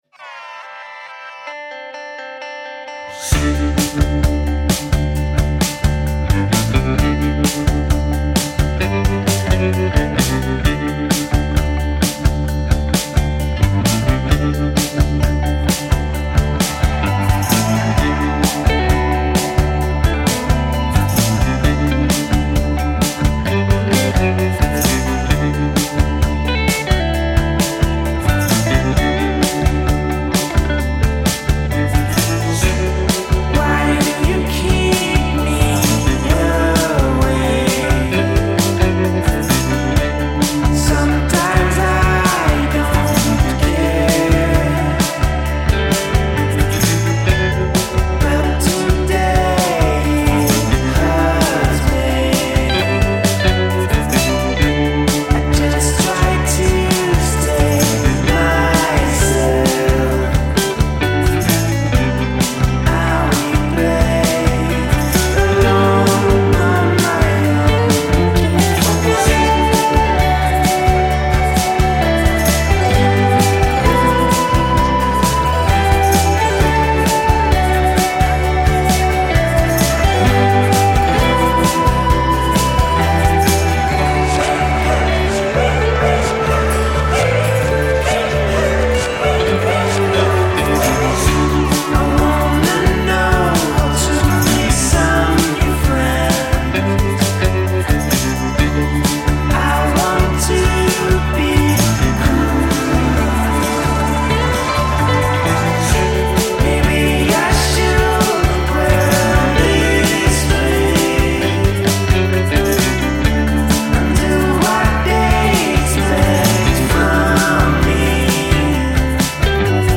Geneva/Lausanne Switzerland based one-man pscyh-pop band